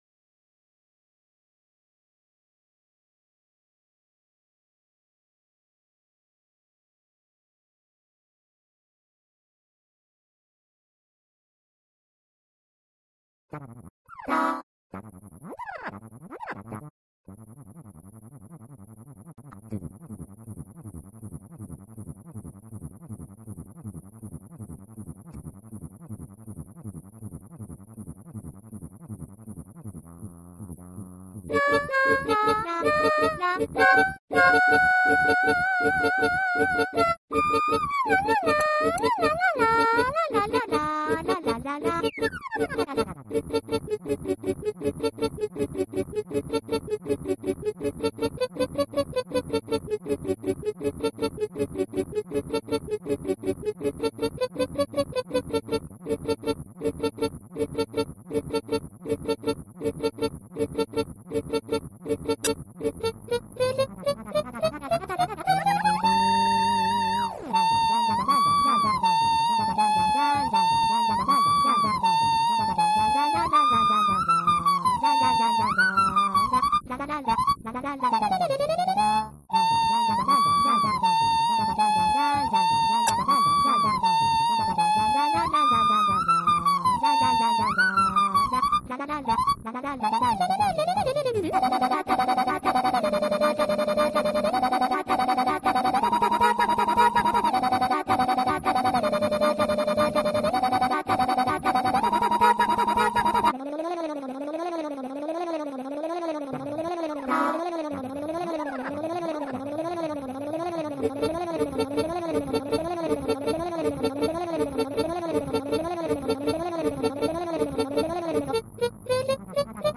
譜面から作ってるんで音は正しいはずですが結構色々適当だったりするんで、ネタと